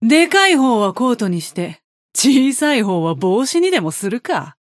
Yamato voice line - I'll make a coat from the big one, and a hat from the small one.